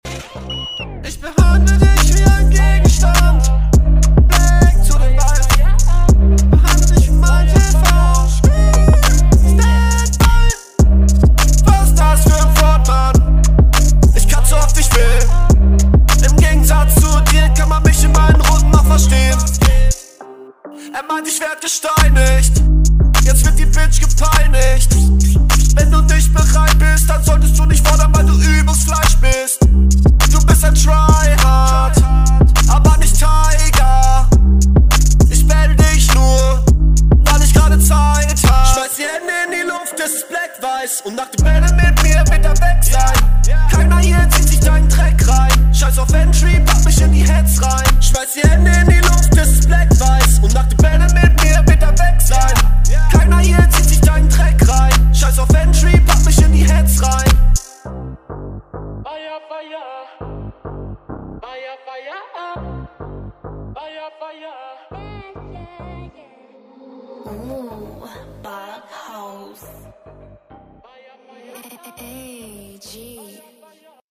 Flow: ok fuck alter der Anfang war ja derbe weird und schief...Flow ist wieder nice …
Der Beat ist echt laut.